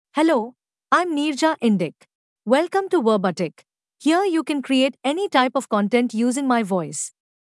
FemaleEnglish (India)
Neerja IndicFemale English AI voice
Neerja Indic is a female AI voice for English (India).
Voice sample
Neerja Indic delivers clear pronunciation with authentic India English intonation, making your content sound professionally produced.